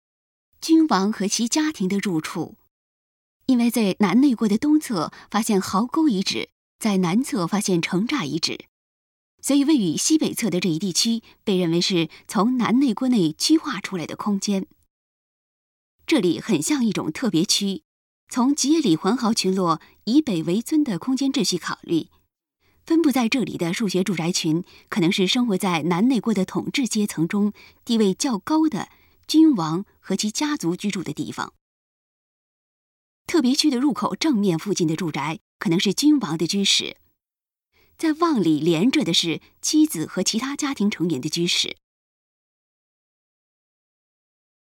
特别区的入口正面附近的住宅，可能是君王的居室，再往里连着的是妻子和其他家庭成员的居室。 语音导览 前一页 下一页 返回手机导游首页 (C)YOSHINOGARI HISTORICAL PARK